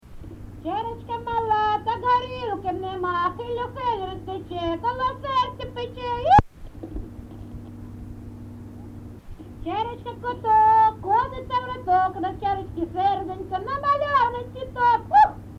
ЖанрТриндички
Місце записус. Гнилиця, Сумський район, Сумська обл., Україна, Слобожанщина